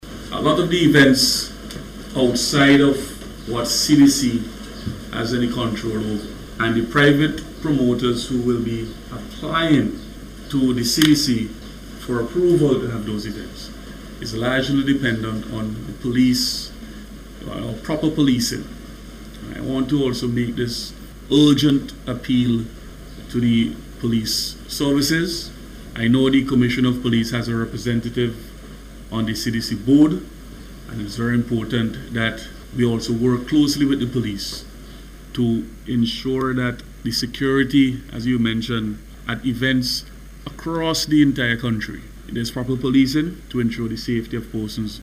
Speaking at a recent News Conference hosted by the Carnival Development Corporation, Minister James noted that proper policing is integral at events to ensure the safety of everyone.